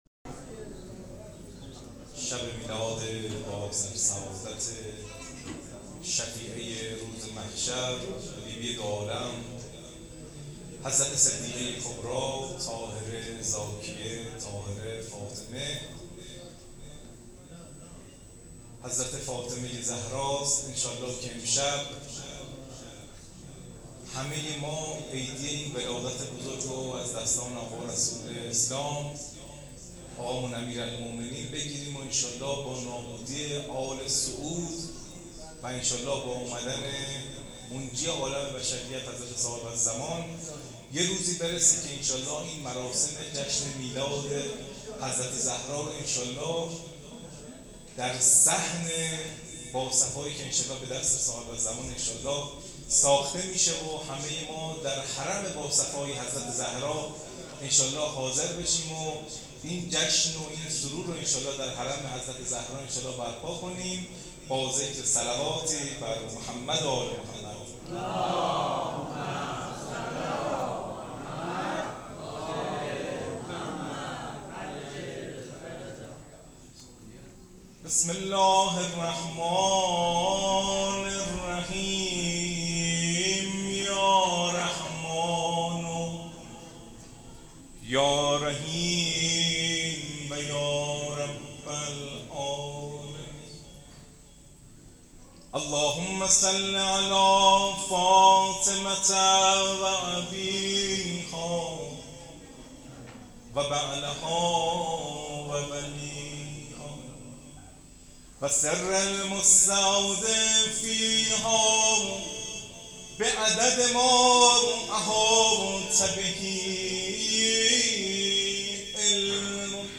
میلاد حضرت زهرا ، شاعر و مداح اهل بیت